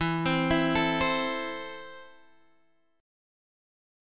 Esus4#5 chord